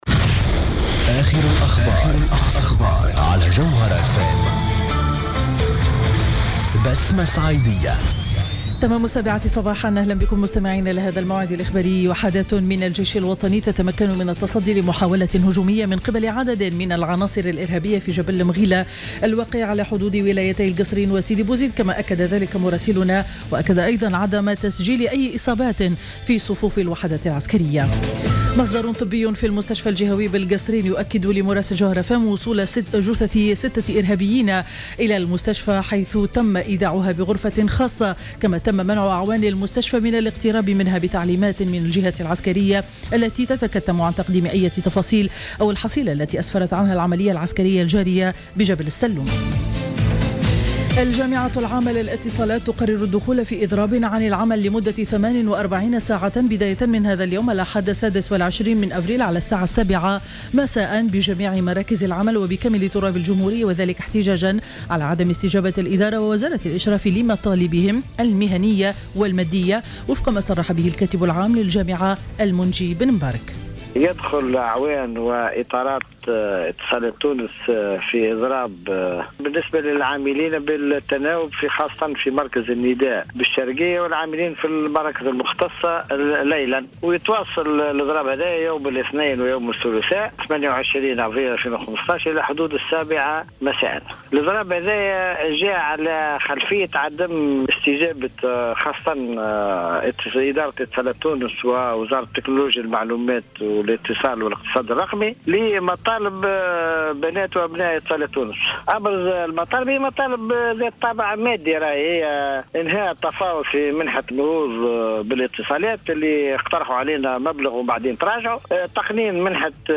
نشرة أخبار السابعة صباحا ليوم الأحد 26 أفريل 2015